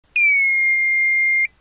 • 電源通電直後にブザー音がしますが、長めのピー音で、少し音質が変化します。
ブザー音（ここをクリックするとサンプル音をダウンロードできます。）